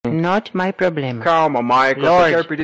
not my problema maico Meme Sound Effect